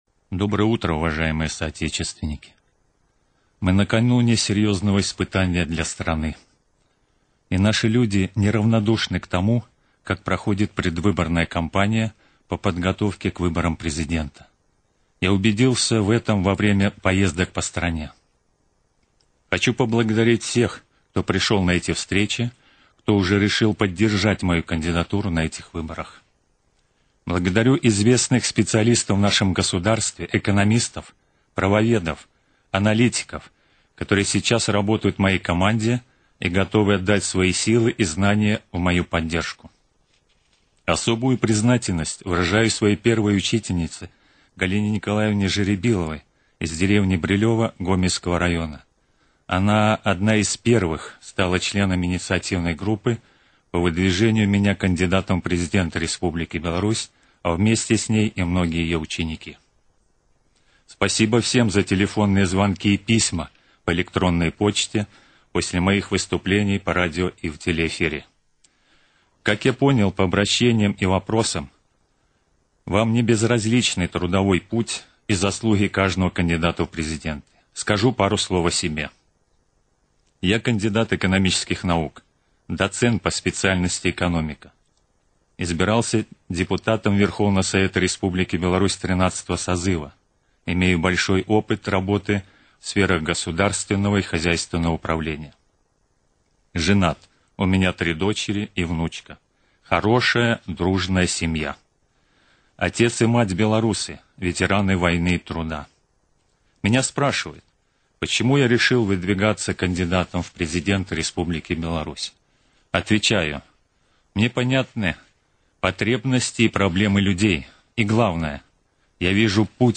Другі радыёвыступ